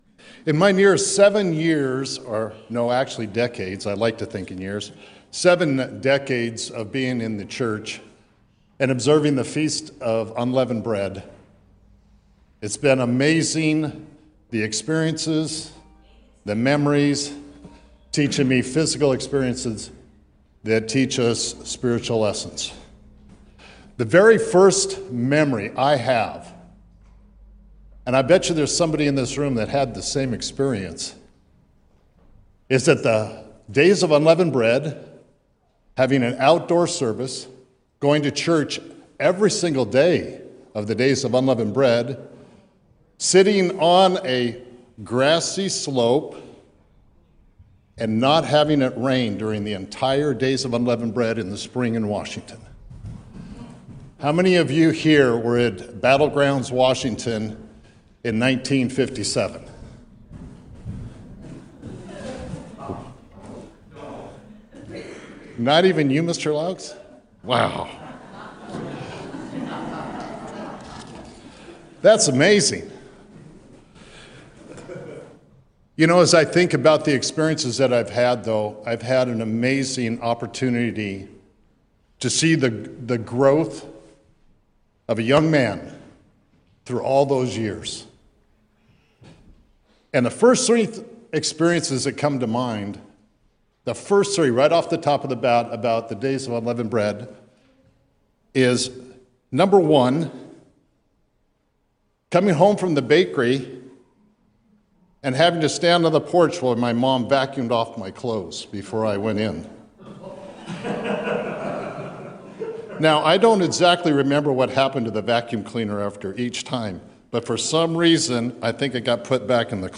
This powerful sermon connects the explosive nature of yeast to the insidious growth of sin, illustrated through a memorable bakery mishap where three pounds of yeast created dough that climbed walls and ceilings. Using the "three Ds" framework—deceive, dominate, destroy—it revealed how sin, like fermentation, progresses through predictable phases but can be stopped with personal "stop signs" during the critical moments of choice.